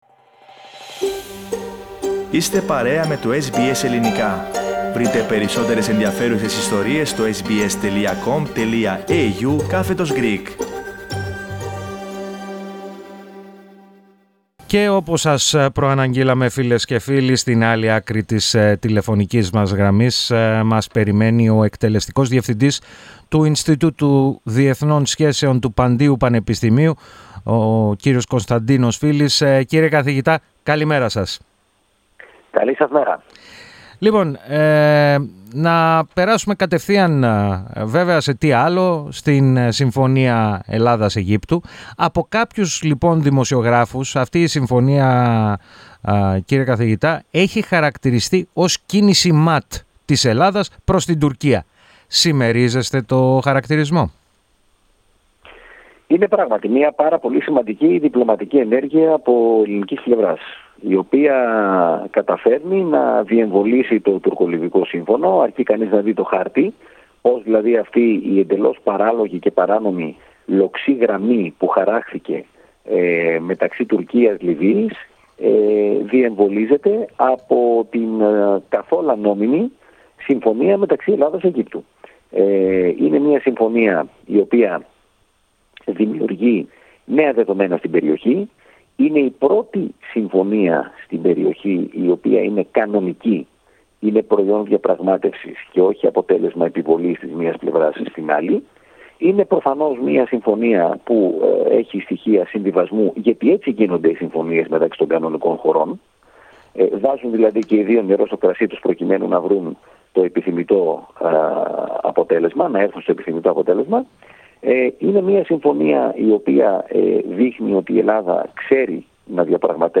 μίλησε στο Ελληνικό Πρόγραμμα της ραδιοφωνίας SBS.